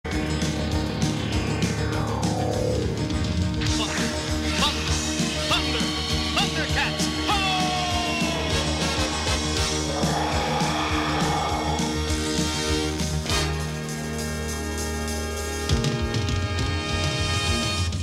Categoría Pop